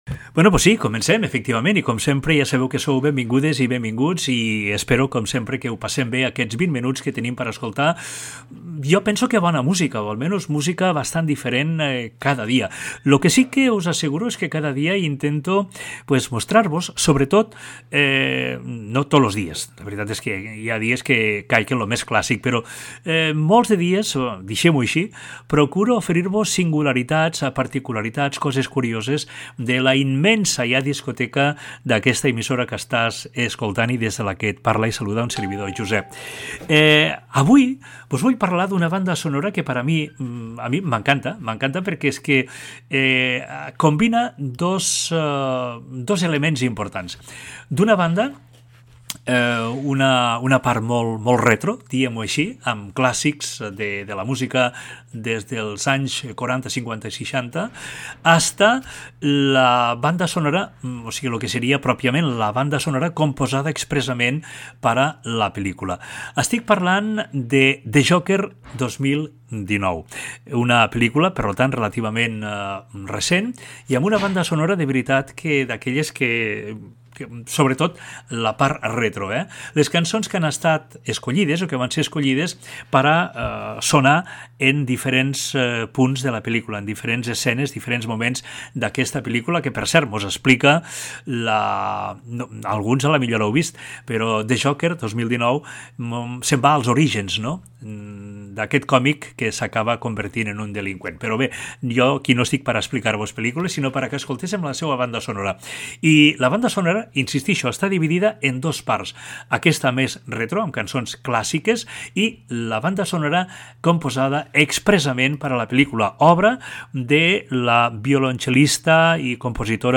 Salutació, presentació inicial i d'un tema musical de la pel·lícula "The jocker"
Musical
FM